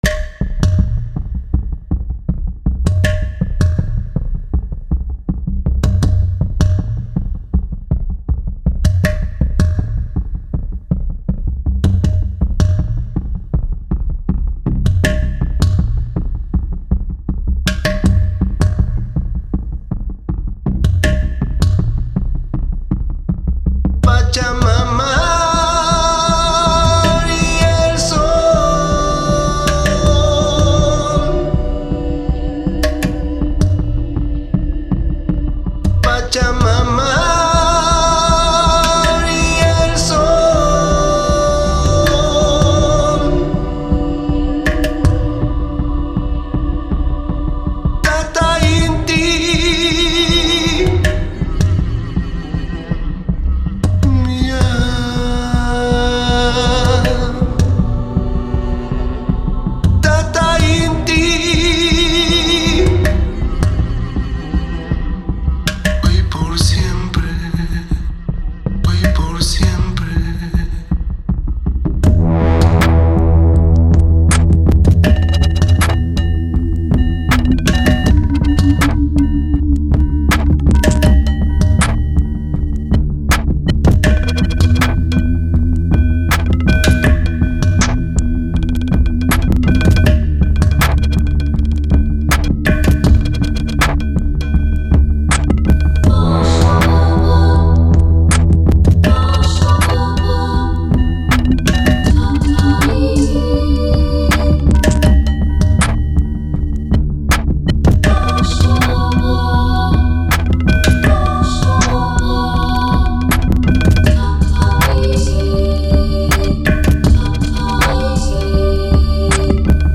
Mittels Automation des Send-Pegels dosiere ich diese Chöre etwas.
Und ohne Abmischung hört es sich so an:
Der Spannungsbogen mit den Loch in der Mitte und dem abgesägten Ende ist zweifellos etwas eigenwillig, aber den Gesamtsound finde ich nicht schlecht – das liegt daran, dass die heutigen Klangerzeuger, seien es Sample-Libraries oder virtuell-analoge Instrumente, ebenso die Effekte, sich in puncto Audioqualität durchgängig auf hohem Niveau bewegen.